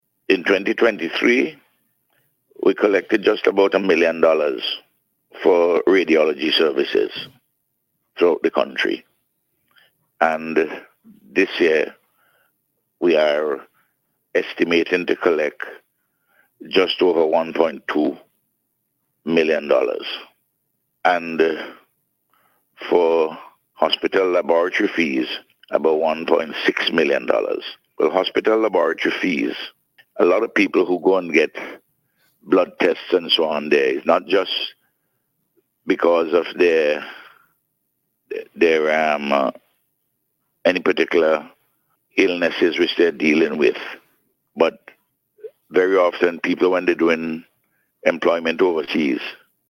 This was among issues addressed by Prime Minister Dr. Ralph Gonsalves on Radio yesterday.